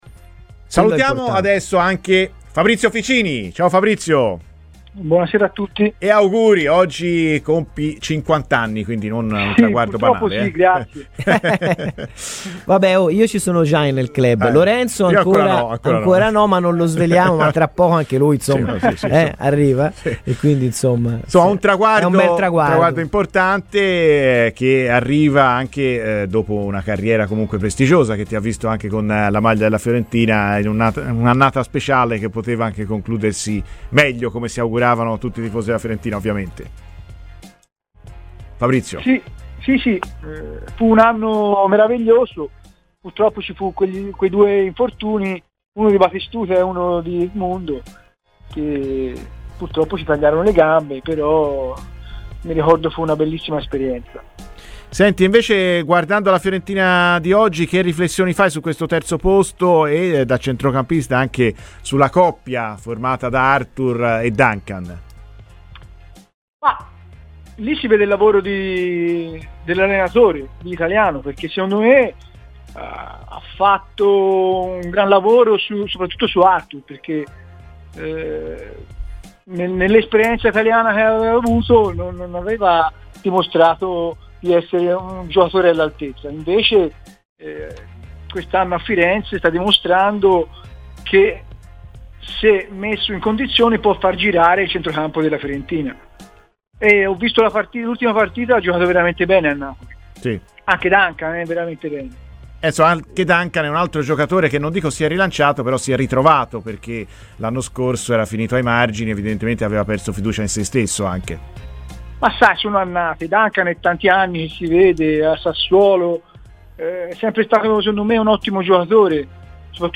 è intervenuto ai microfoni di Radio FirenzeViola, durante la trasmissione "Viola amore mio", partendo da una riflessione sulla Fiorentina e sul centrocampo Arthur-Duncan